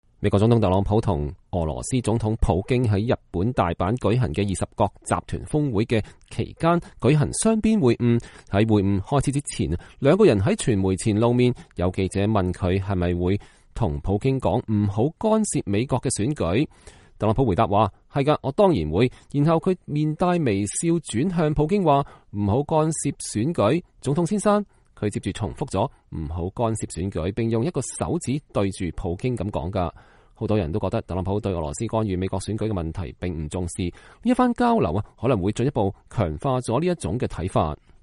美國總統特朗普以輕鬆愉快的方式警告俄羅斯總統普京不要干預美國選舉。特朗普和普京在日本大阪舉行的20國集團峰會的間歇進行了雙邊會晤，會晤開始前兩人在媒體前露面。有記者問他是不是會告訴普京不要干涉美國選舉。
“是的，我當然會，”特朗普回答說，然後他面帶微笑地轉向普京說：“不要干涉選舉，總統。”他接著重複了“不要干涉選舉”，並用一個手指對著普京。